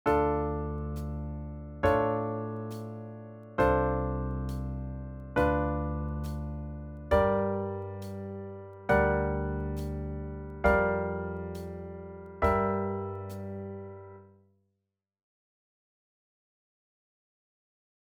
バラード
4拍目の裏に高揚感をもつと同じテンポだけどゆったり聞こえ、演奏のもたつきを防げるような気がします
バラード.wav